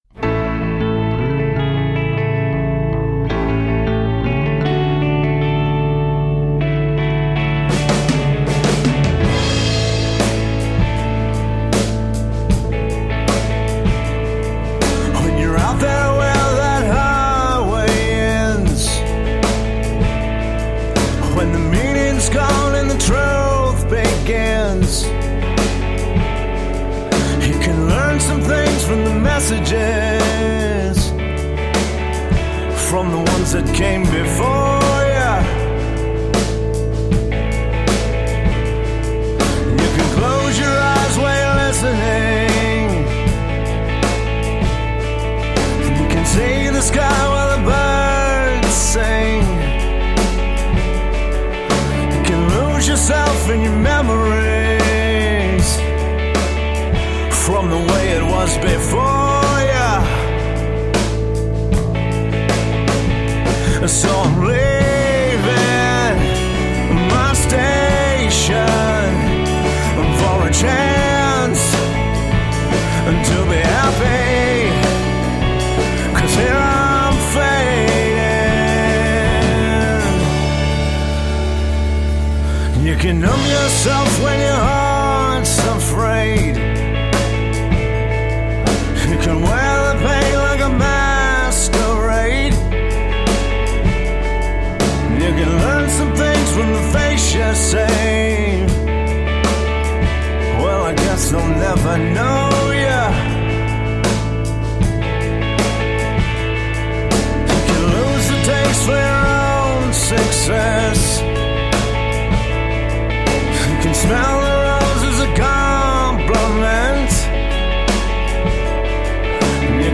Indie rock when lyrics were king.
session drummer
Tagged as: Alt Rock, Other